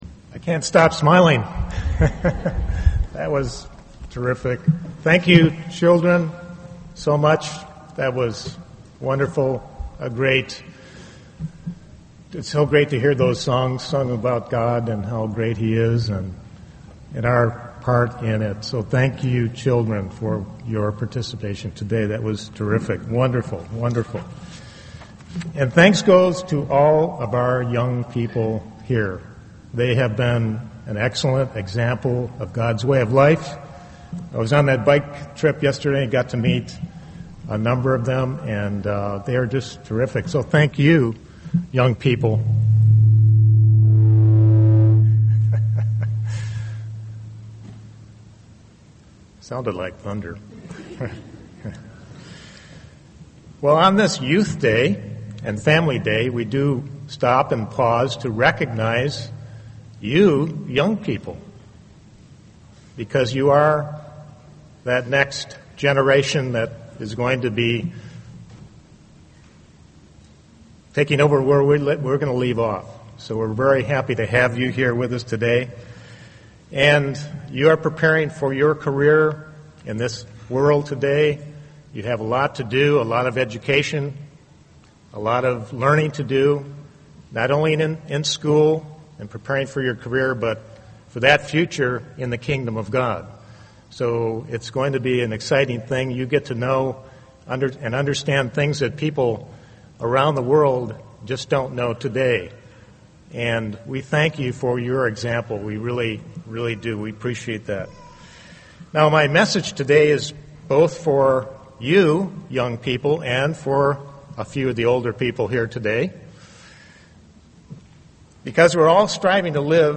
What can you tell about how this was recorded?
This sermon was given at the Jekyll Island, Georgia 2015 Feast site.